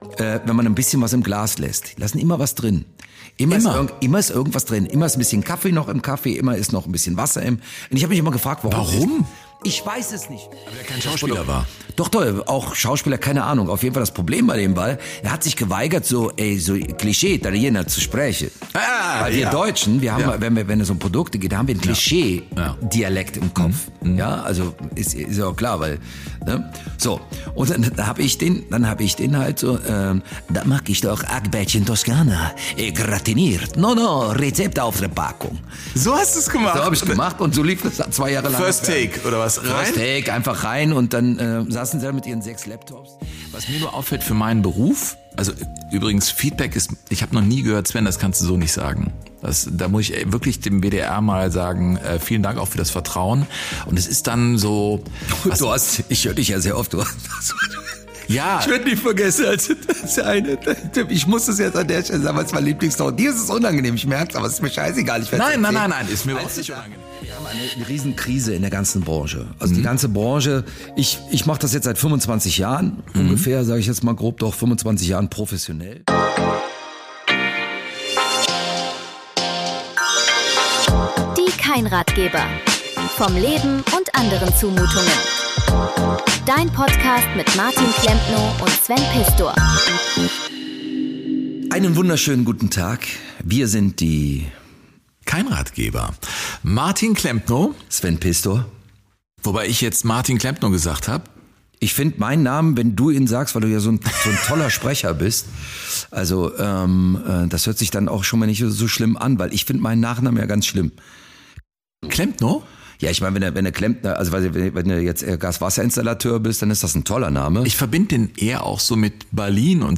Beschreibung vor 1 Woche DIE KEINRATGEBER – Episode 001 - Zwischen Fußball, Fernsehen, Bühne und dem Rest des Wahnsinns - In der ersten Folge von DIE KEINRATGEBER lernst du die beiden Hosts kennen: Sportjournalist Sven Pistor und Schauspieler und Comedian Martin Klempnow. Die beiden sprechen darüber, woher sie sich kennen, was sie beruflich geprägt hat – und warum sie sich ausgerechnet jetzt gedacht haben: Wir machen einen Podcast. Es geht um Fußball im Radio, Satire im Fernsehen, Comedy auf der Bühne, Medienkrisen, Social Media, öffentliche Kritik – und um die sehr wichtige Frage, warum manche Menschen eigentlich immer einen Rest im Glas lassen.